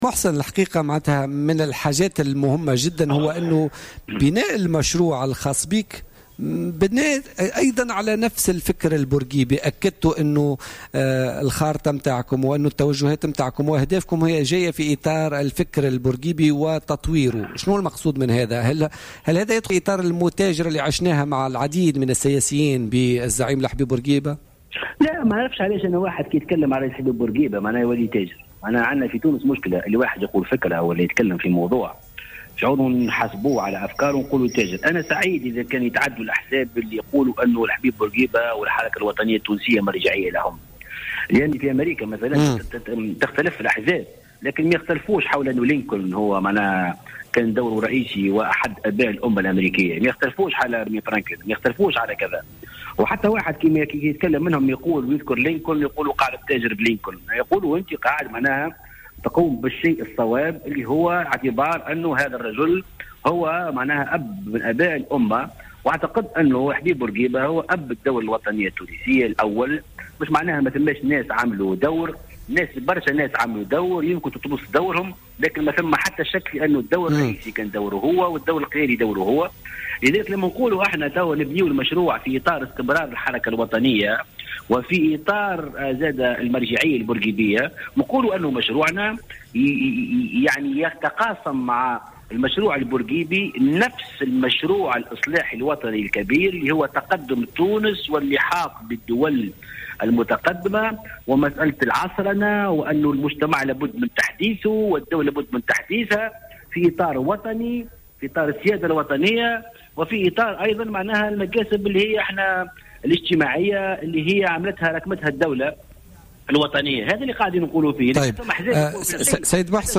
نفى القيادي في حركة مشروع تونس محسن مرزوق في تصريح للجوهرة أف أم في برنامج بوليتكا لليوم الأربعاء 6 أفريل 2016 أن يكون حضور الفكر البورقيبي في مشروعه يندرج في إطار المتاجرة بهذا الفكر على حد تعبيره.